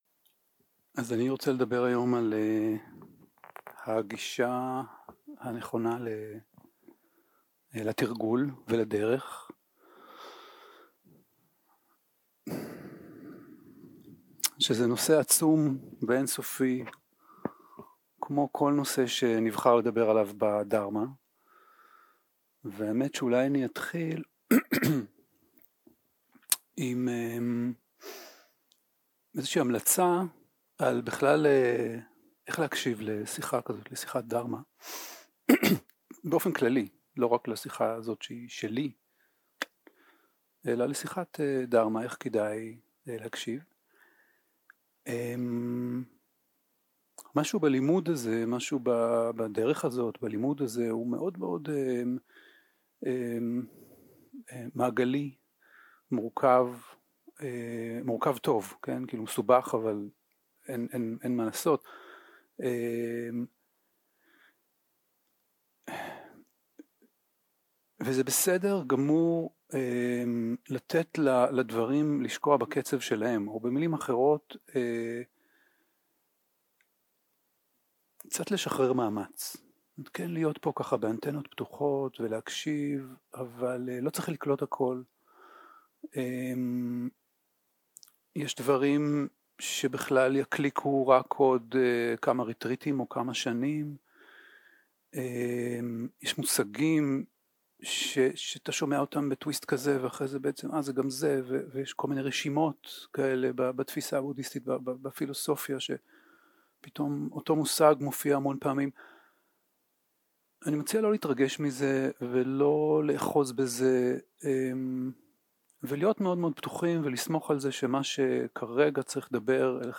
יום 2 - הקלטה 4 - ערב - שיחת דהרמה - איך כדאי לשים לב